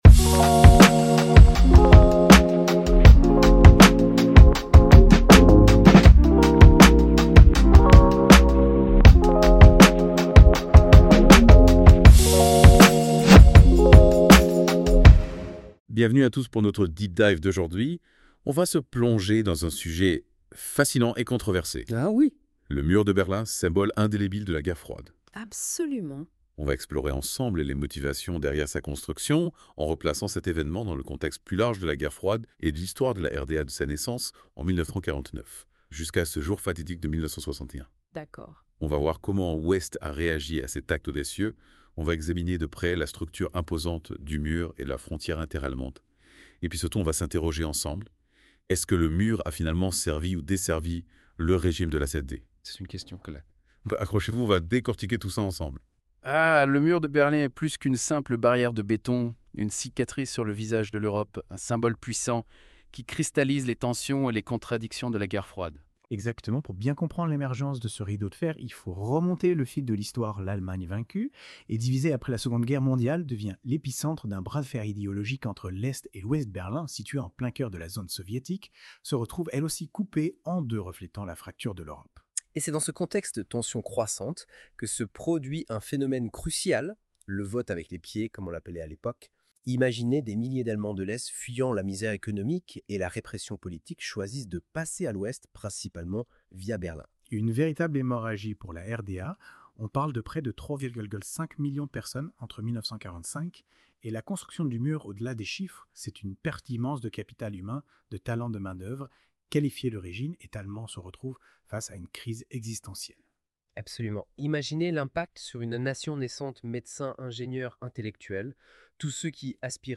Aussprachekorrektur bei einigen Items nötig: Brandt, Kennedy: « Ich bin ein Berliner »